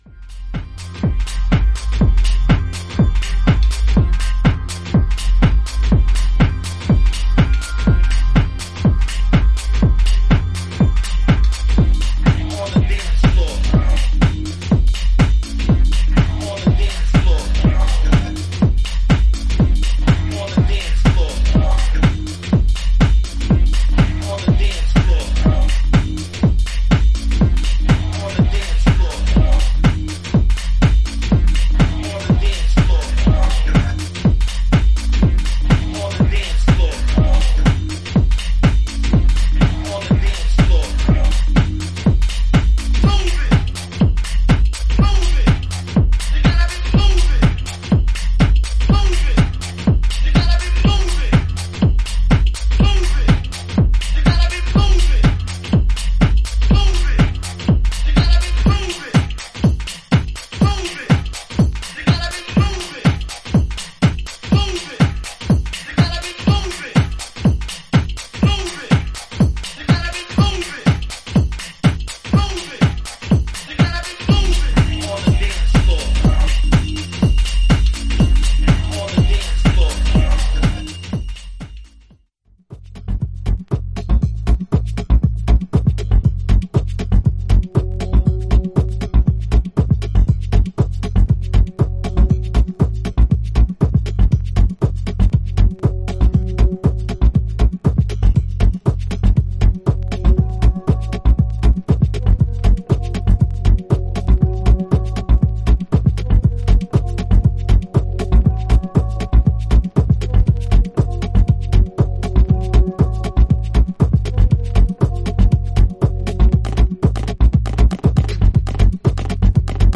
ザックリとした質感のビートにミニマルなヴォイス・サンプルがリフレインする
エレクトロニックながらも柔らかなメロディが心地良い
ミニマル且つグルーヴィーにハメる